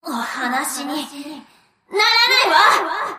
Enemy_Voice_Abyssal_Pacific_Princess_Damaged_Under_Attack.mp3